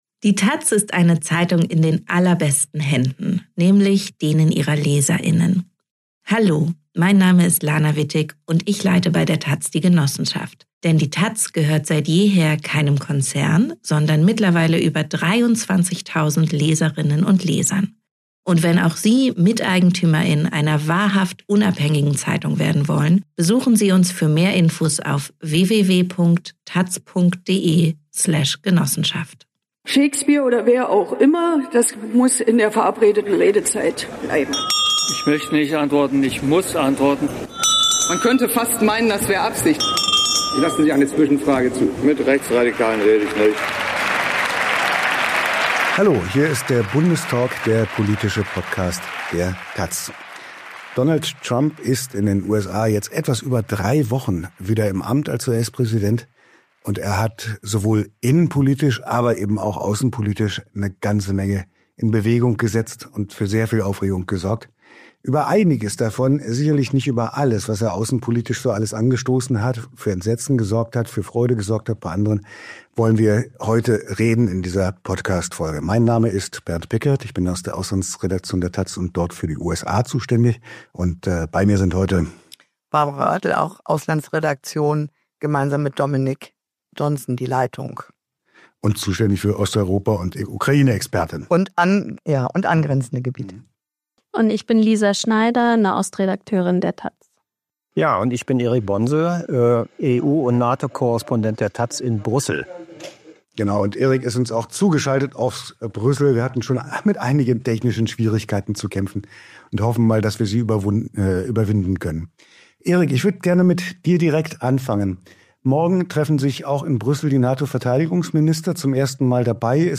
Einmal in der Woche blicken taz-Redakteur*innen auf die politische Lage im In- und Ausland